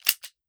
38 SPL Revolver - Dry Trigger 001.wav